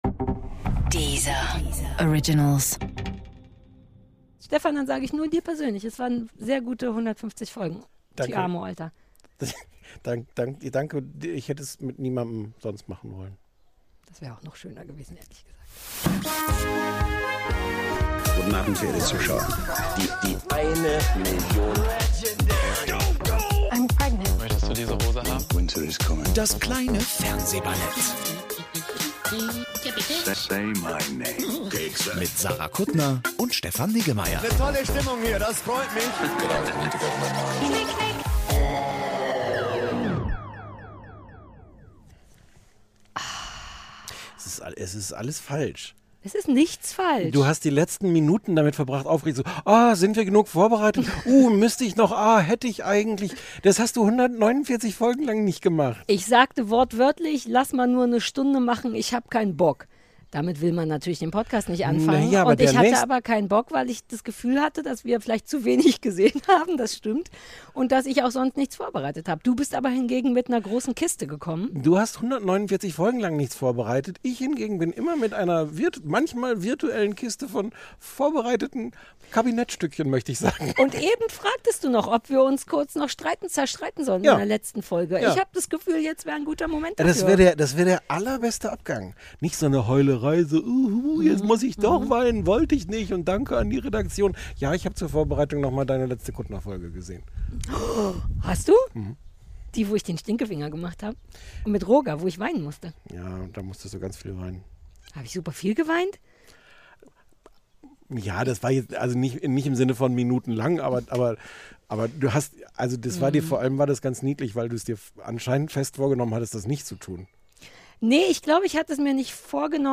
Wir verabschieden uns mit einer Open-Air-Folge auf dem Kuttnerschen Landsitz, besprechen noch schnell den „Fluch der Chippendales“ (ARD) und bedanken uns mit dem ersten Auftritt unserer Band „AB“ beim besten Publikum der Welt.